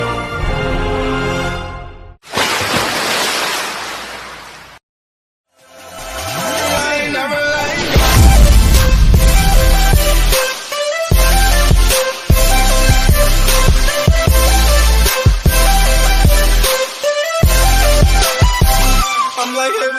Light Drizzle
Light Drizzle is a free nature sound effect available for download in MP3 format.
332_light_drizzle.mp3